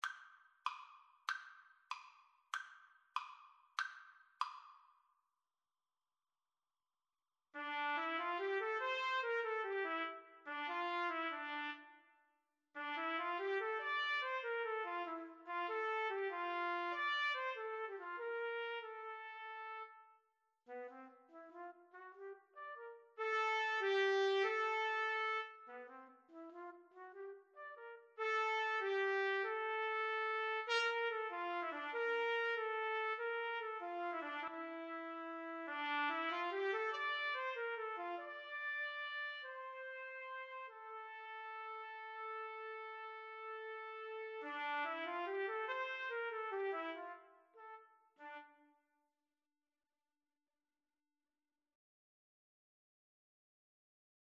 Free Sheet music for Trumpet Duet
D minor (Sounding Pitch) E minor (Trumpet in Bb) (View more D minor Music for Trumpet Duet )
Allegro piacevole . = 96 (View more music marked Allegro)
6/8 (View more 6/8 Music)
Classical (View more Classical Trumpet Duet Music)